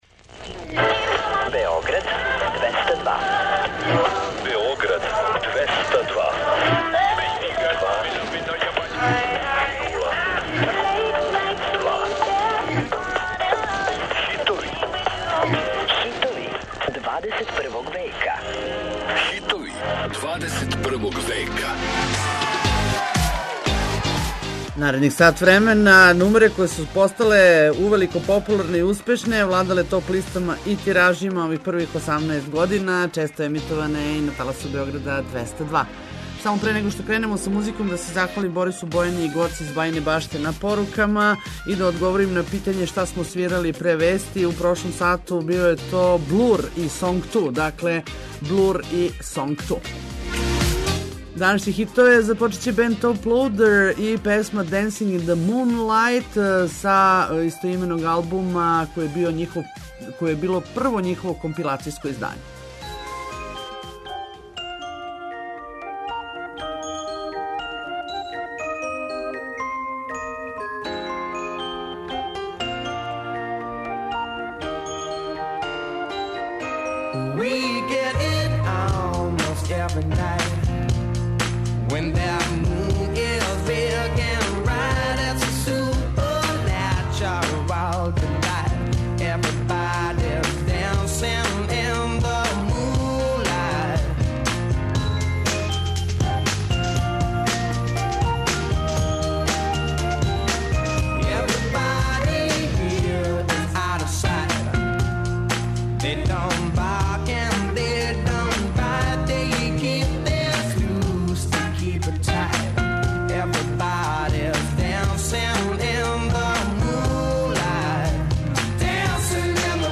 Очекују вас највећи хитови 21. века!